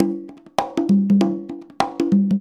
100 CONGAS03.wav